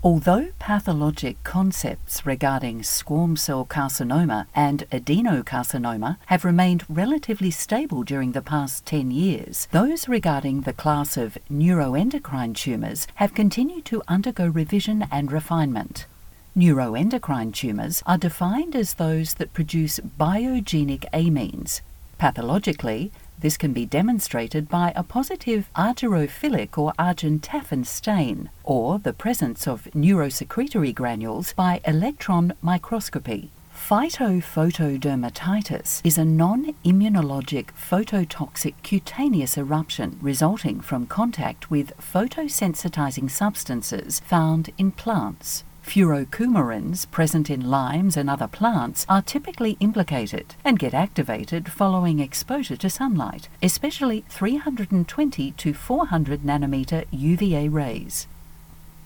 Female
English (Australian)
Medical Narrations
Complex Terminology/Language
Words that describe my voice are sensual, conversational, authoritative.
All our voice actors have professional broadcast quality recording studios.